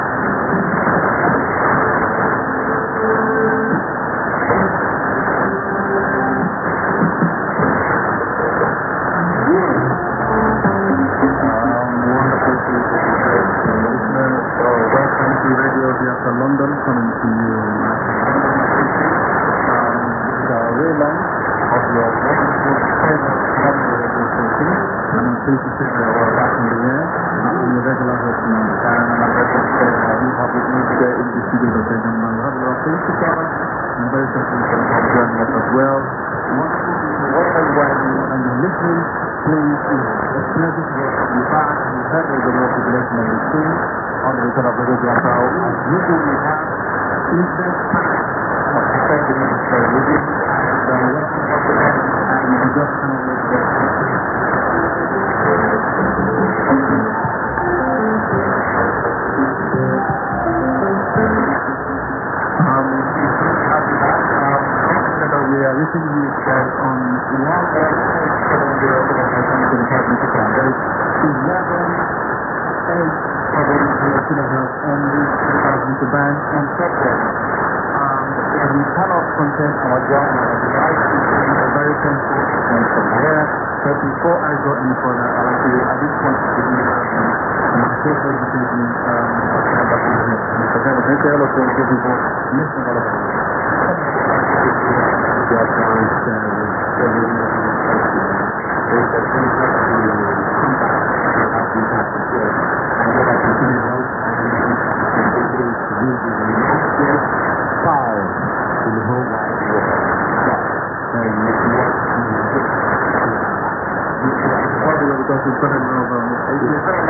IS: interval signal
ID: identification announcement